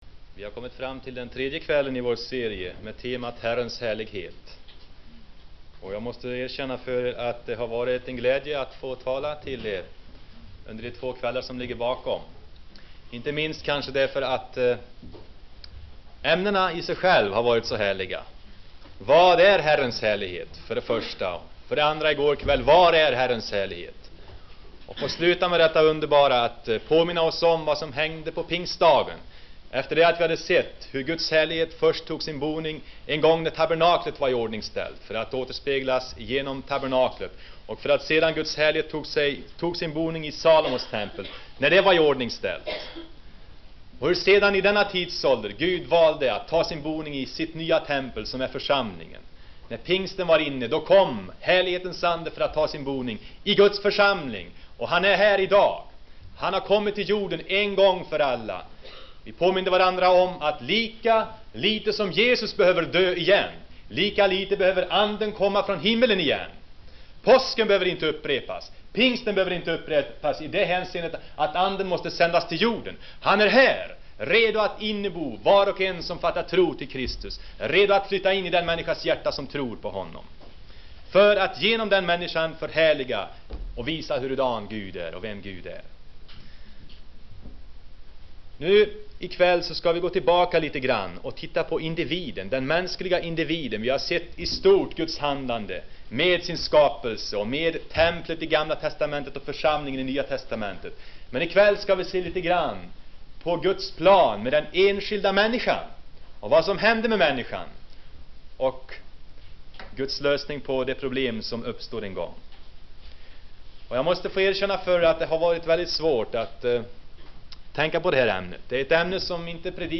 Inspelad i Saronförsamlingen, KFUM Göteborg 1974-01-25.